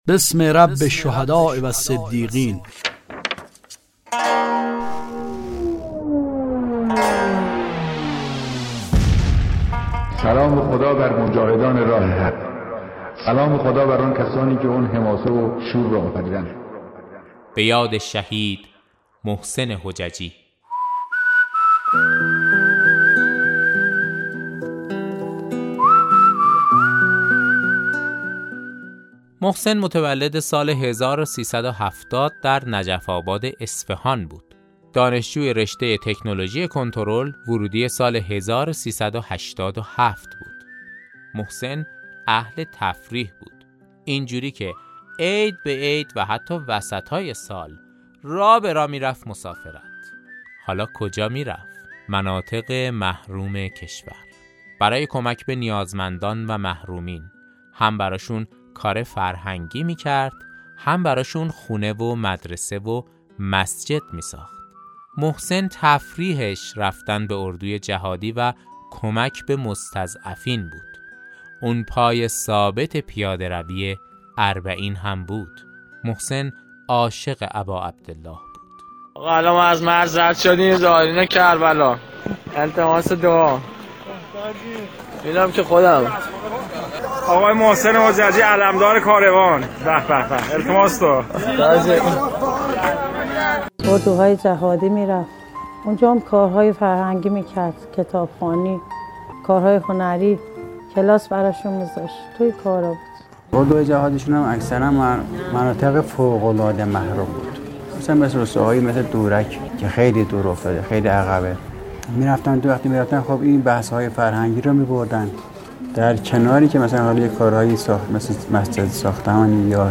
داستان صوتی شهید محسن حججی